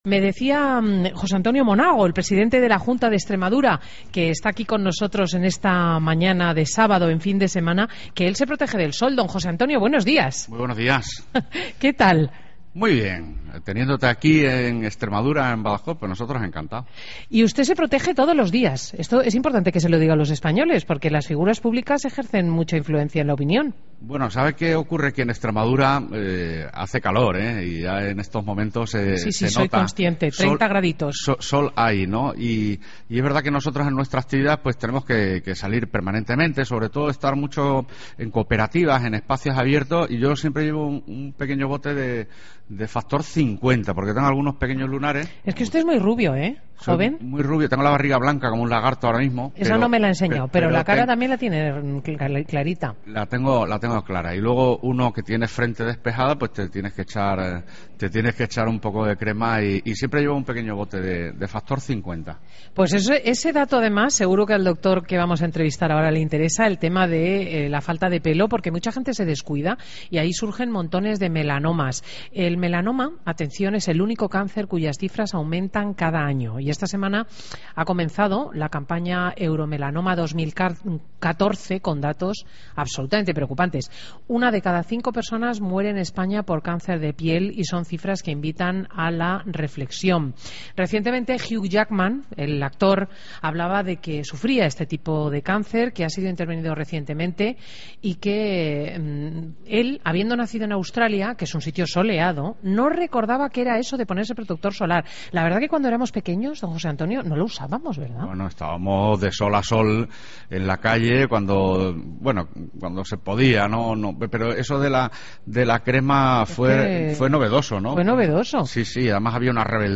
José Antonio Monago, presidente de Extremadura, en Fin de Semana desde Badajoz
Entrevistas en Fin de Semana